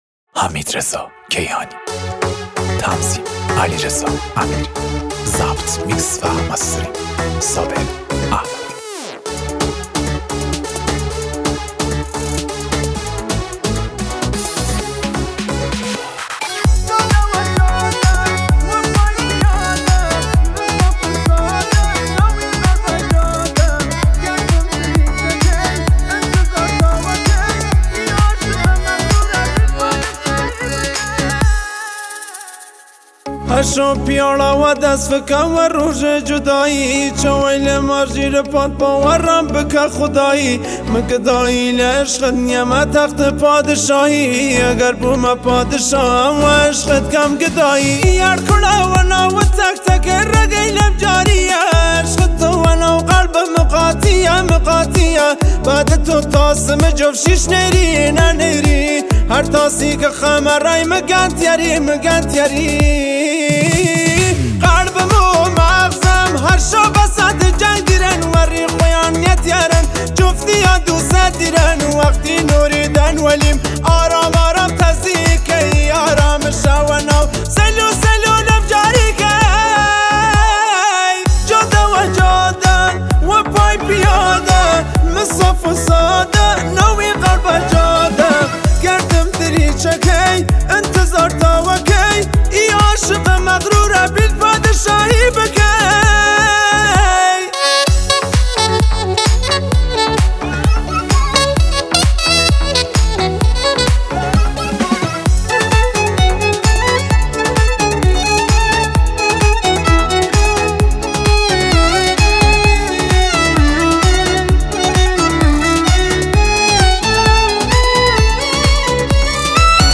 موزیک کردی